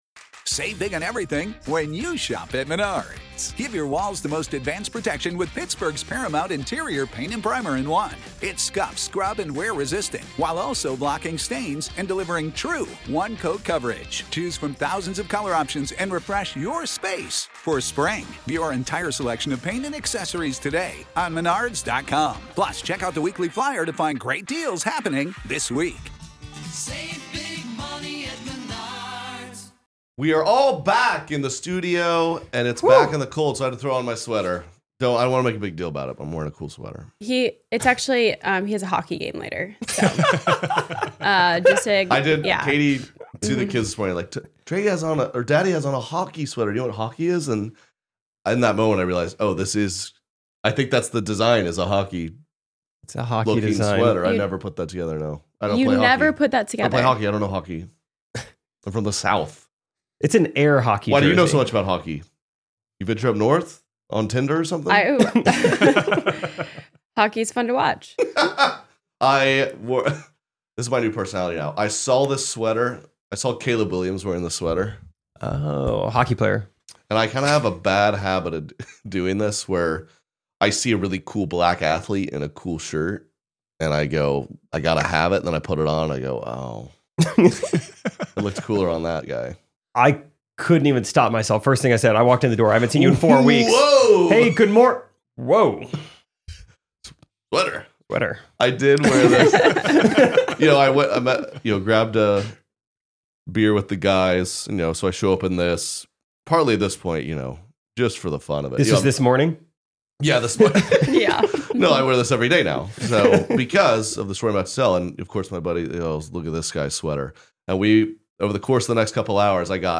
We’re back in the studio… in the cold… after Hawaii absolutely wrecked our sleep schedules